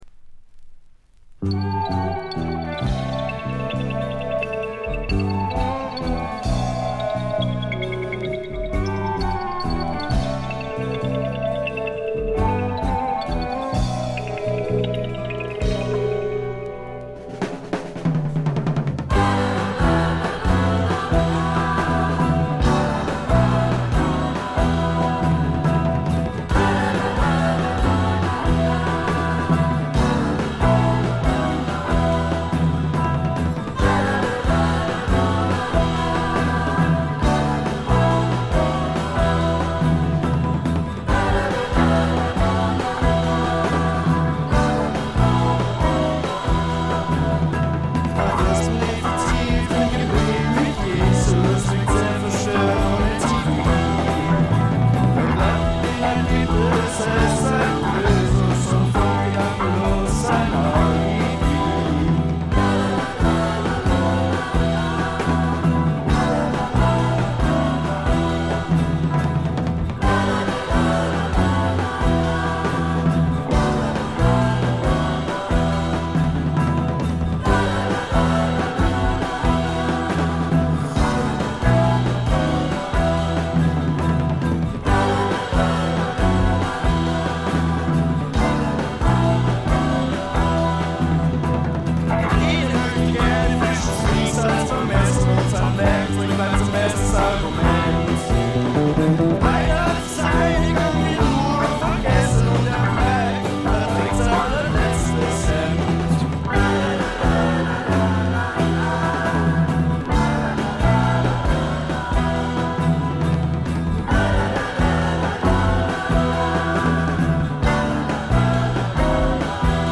全体にバックグラウンドノイズが出ていますが静音部で分かる程度。散発的なプツ音が2-3回ほど。
ジャーマン・アンダーグラウンド・プログレッシヴ・サイケの雄が放った名作。
試聴曲は現品からの取り込み音源です。